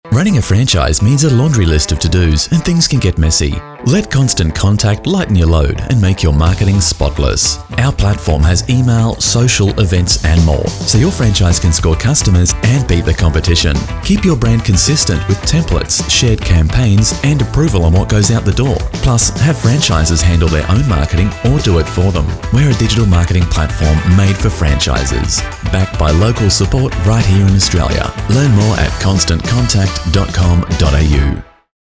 Brisbane Voiceover Services
ctct_franchise_vo.mp3